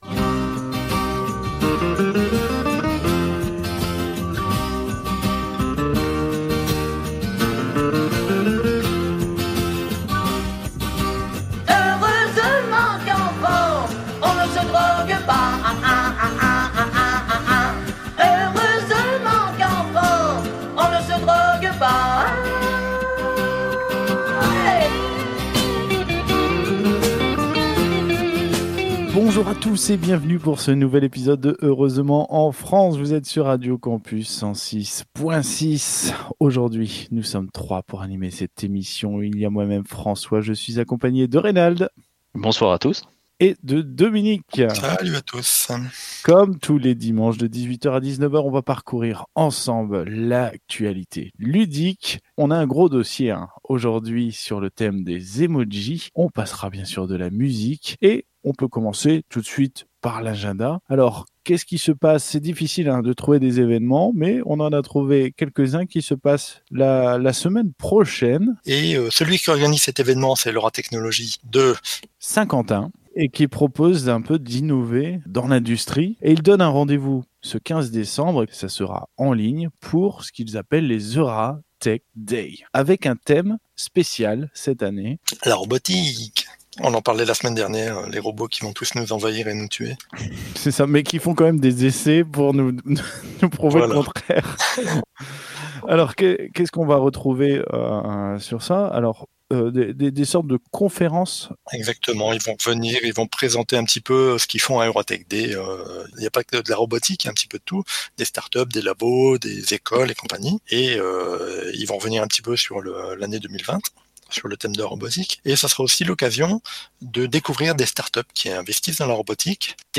Au sommaire de cet épisode diffusé le 13 décembre 2020 sur Radio Campus 106.6 :